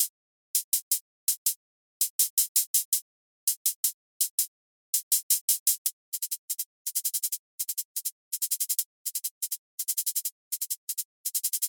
5 Hihat
Sie läuft teilweise auf 16teln, dann wie-der auf 32tel oder sogar 64tel und das dann auch mal gerne triolisch.
So sind die Transienten gut zu hören und es kommt nicht zu einer starken Überlagerung der Sounds.
Hihat-2.mp3